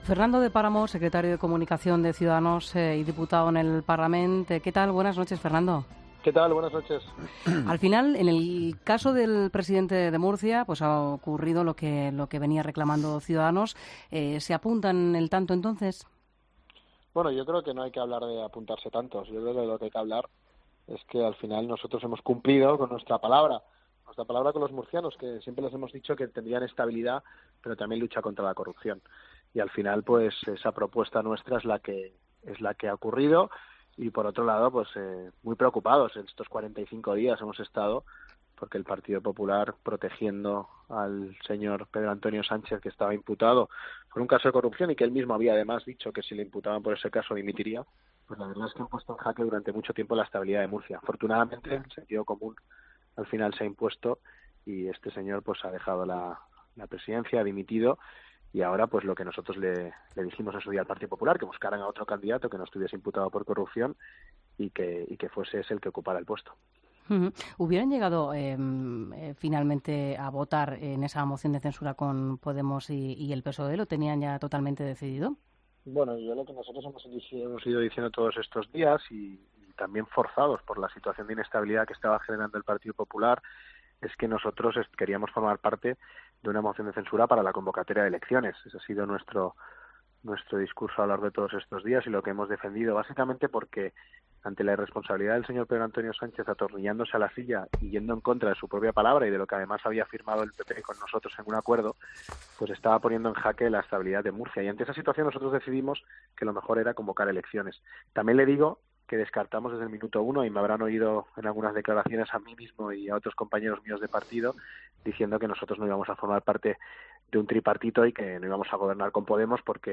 Entrevista a Fernando de Páramo, Secretario de Comunicación de Ciudadanos